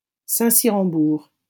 -Saint-Cyr-en-Bourg.wav Audio pronunciation file from the Lingua Libre project.